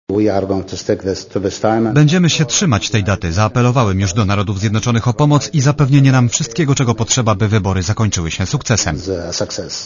przyszłego roku, mówił na konferencji prasowej w
Posłuchaj komentarza Ijada Alawiego